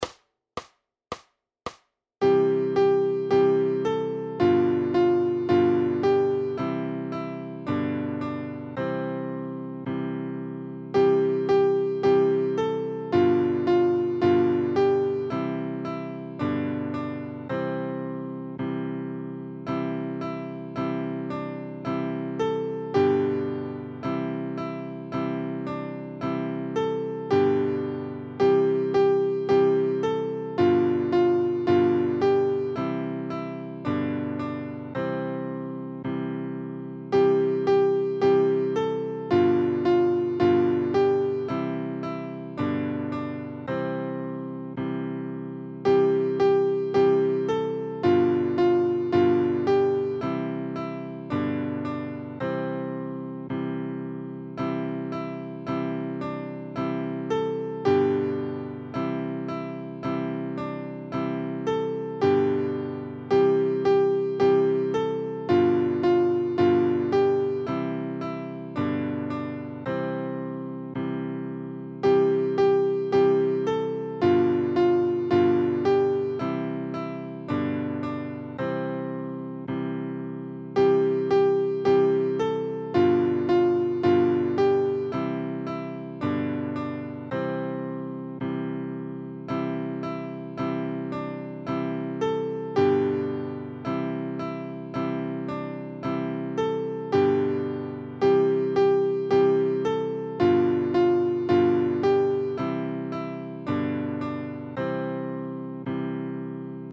Koledy na 2 akordy
Aranžmá Noty na snadný sólo klavír
Hudební žánr Vánoční koledy